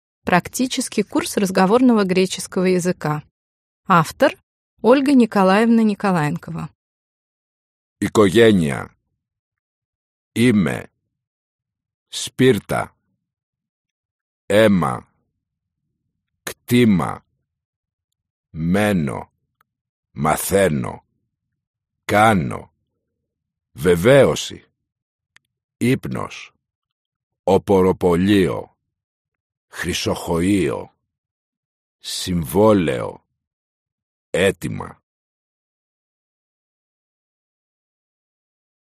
Аудиокнига Практический курс разговорного греческого языка | Библиотека аудиокниг